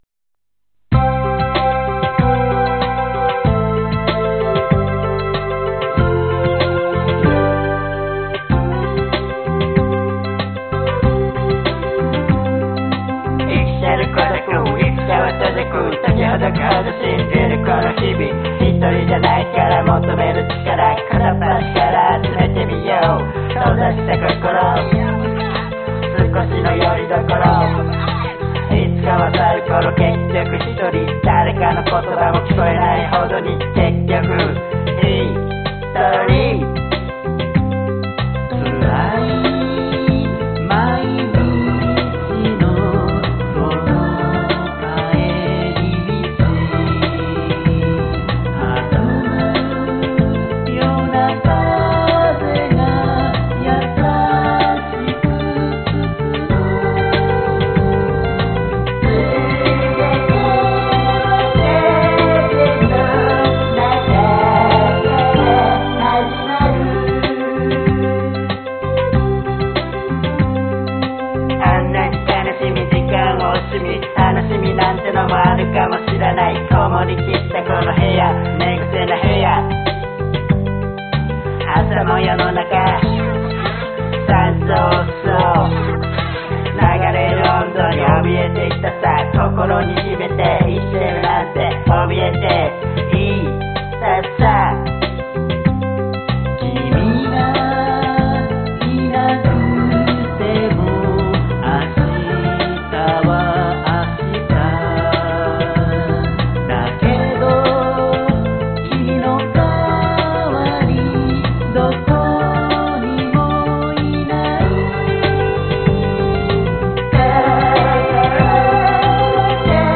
歌は下手です。
元々はフォークソングでメッセージソングだったのですが、今回RAP的にしてみました。
かっこ良くRAPするのってかなり難しくてあまりうまい出来ではないなと思いつつも、曲の劇的な変化にほくそえんでおります。